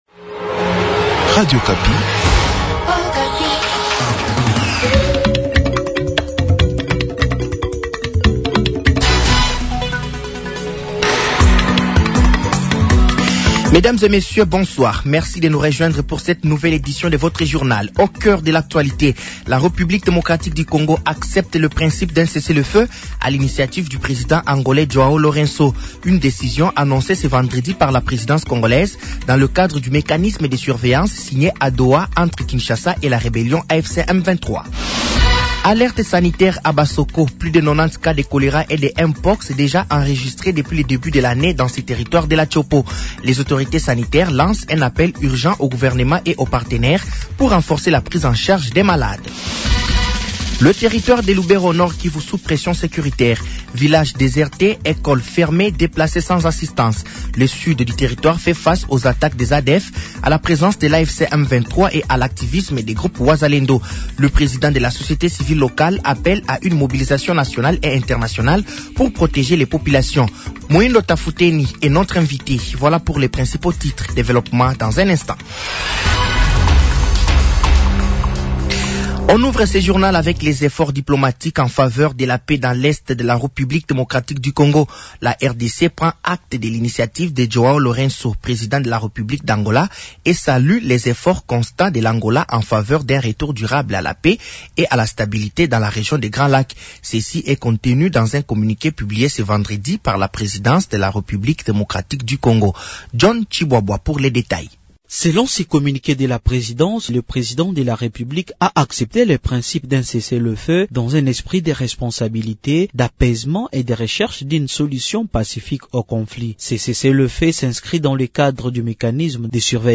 Journal français de 18h de ce vendredi 13 février 2026